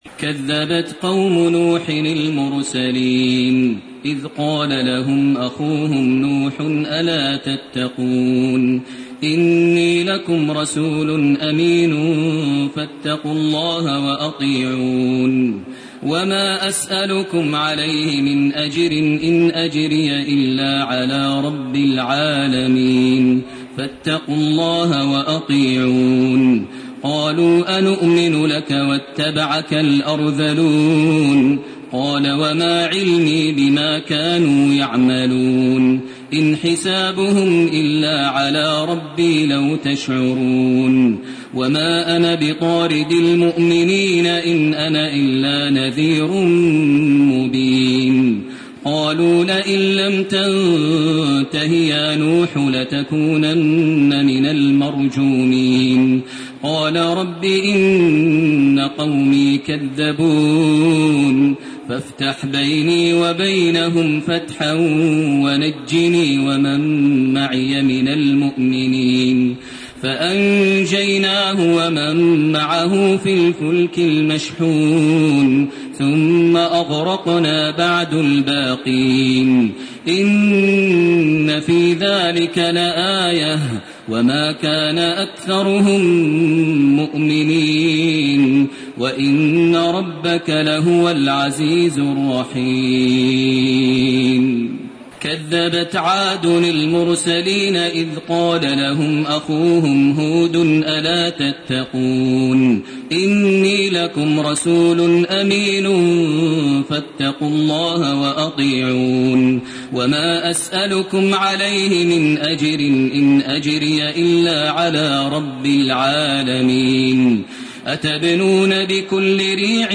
سورة الشعراء من 105 الي اخرها وسورة النمل من 1 الي 53 > تراويح ١٤٣٢ > التراويح - تلاوات ماهر المعيقلي